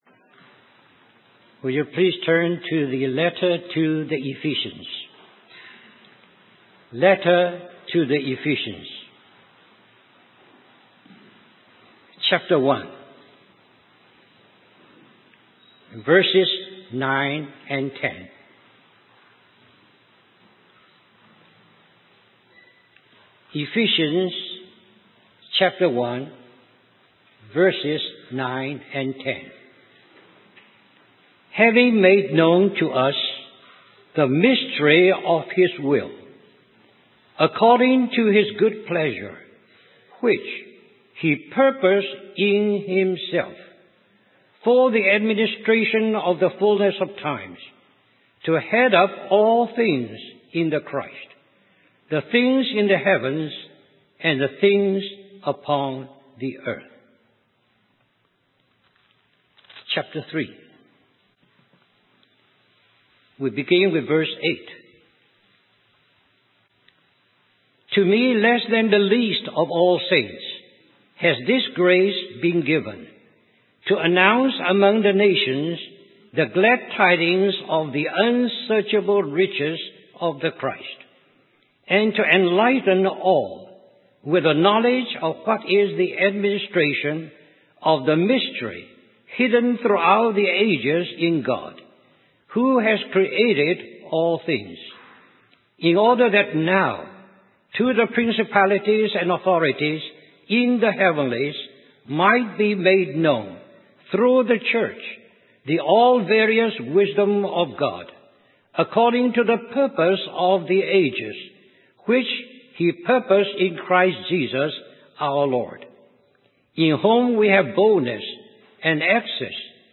In this sermon, the speaker discusses the theme of God's eternal purpose from three different perspectives: Christ, the Church, and Christians. He explains that Jesus came into the world to find His bride, just as Adam sought a companion. The speaker emphasizes the significance of the water and blood that flowed from Jesus' side when He was pierced, representing the remission of sins and His life.